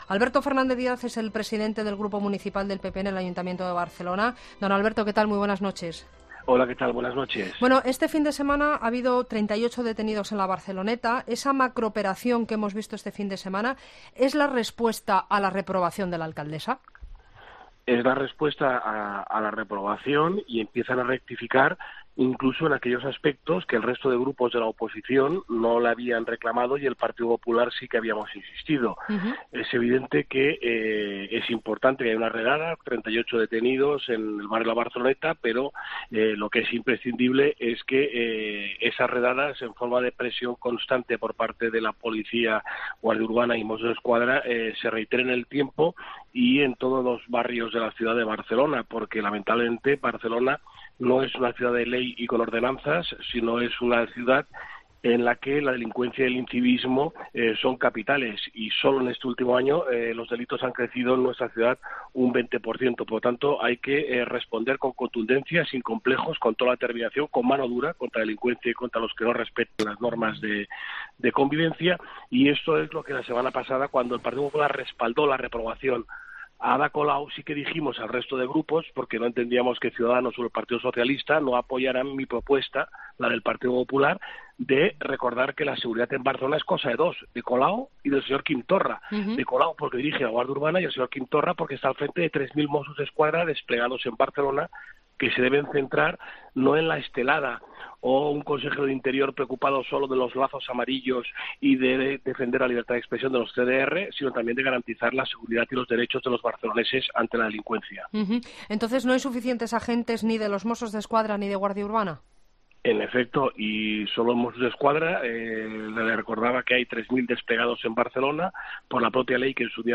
Entrevista a Alberto Fernández Díaz en ‘La Linterna’, lunes 13 de agosto de 2018